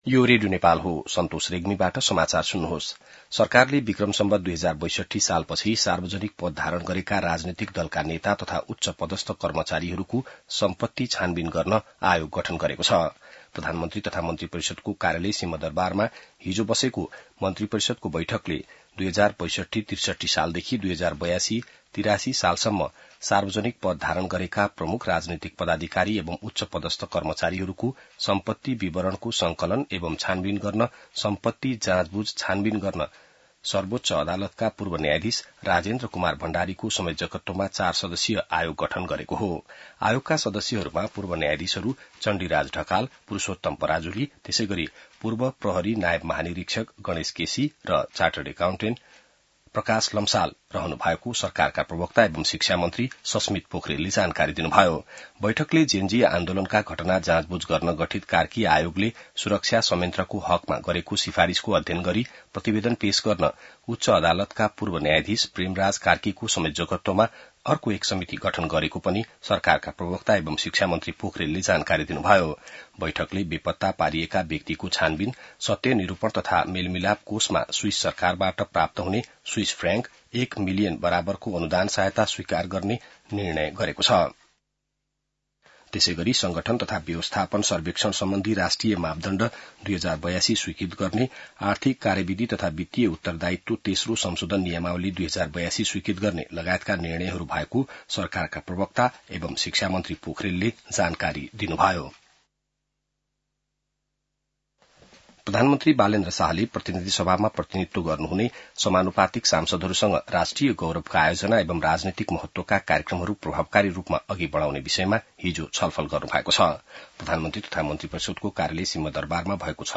बिहान ६ बजेको नेपाली समाचार : ३ वैशाख , २०८३